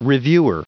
Prononciation du mot reviewer en anglais (fichier audio)
Prononciation du mot : reviewer